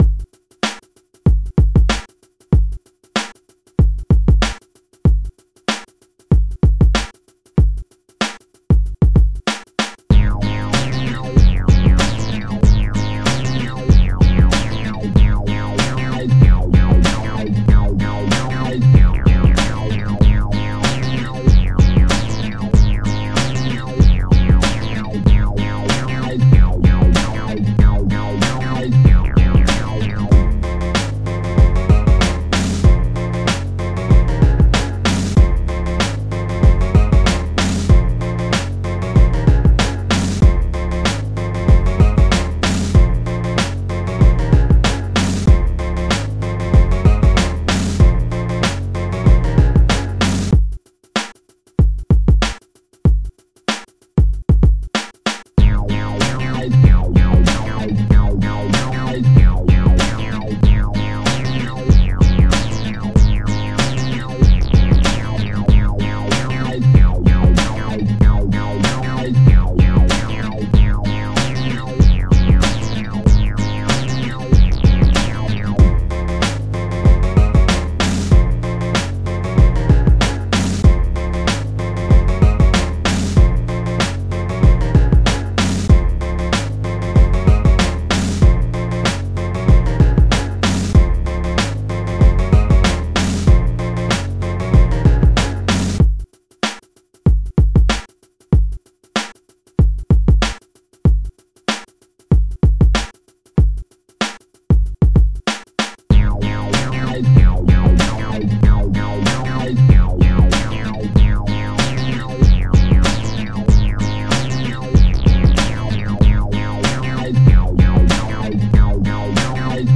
Instrumental - Clean Version - Lyrics